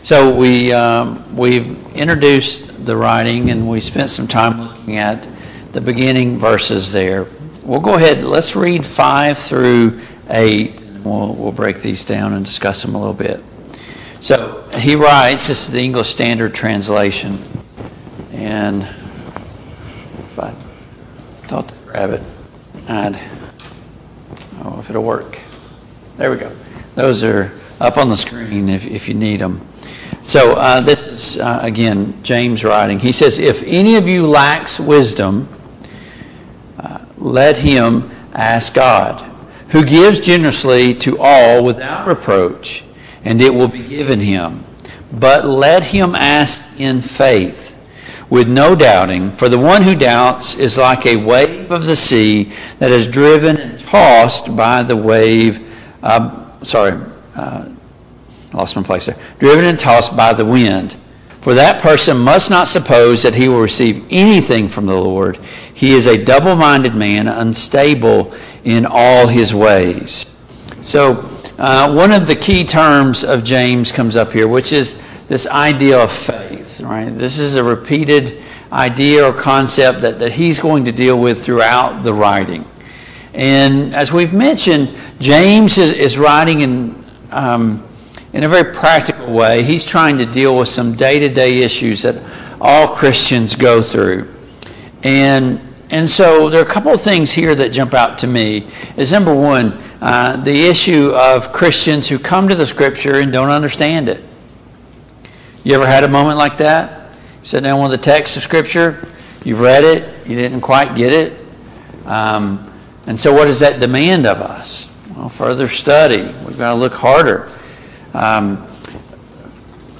Study of James and 1&2 Peter Passage: James 1:5-11 Service Type: Family Bible Hour Topics